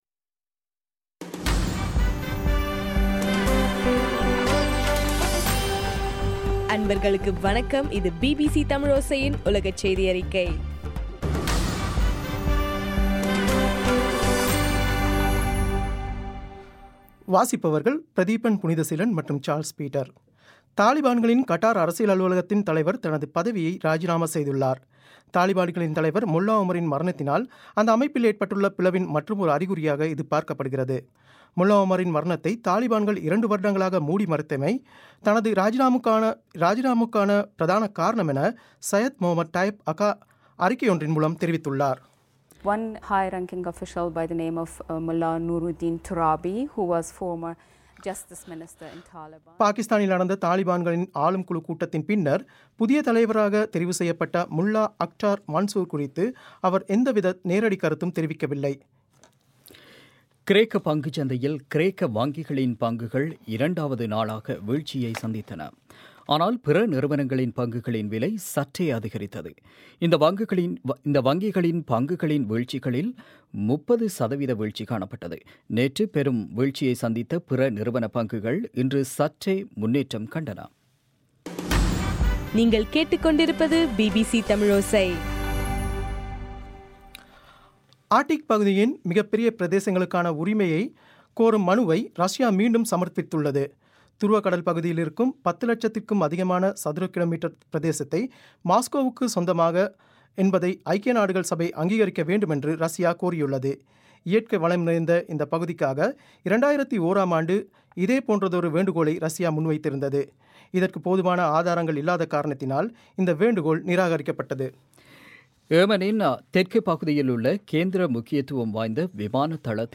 ஆகஸ்ட் 4 பிபிசியின் உலகச் செய்திகள்